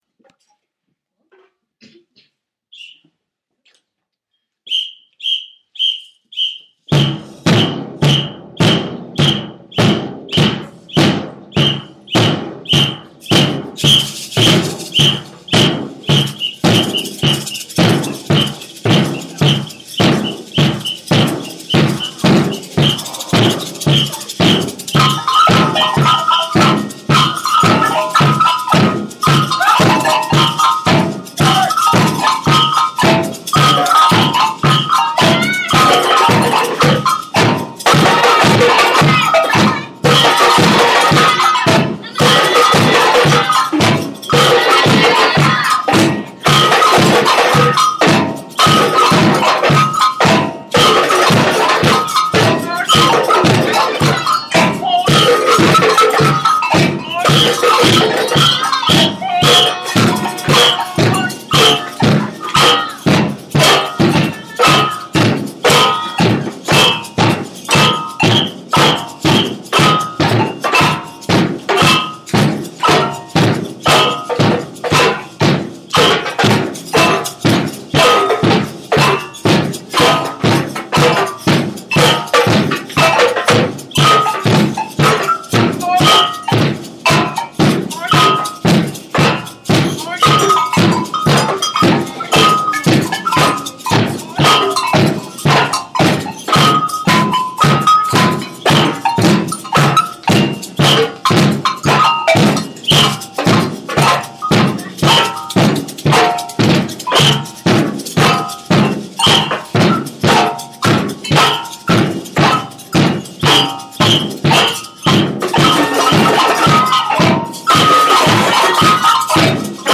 8BIM samba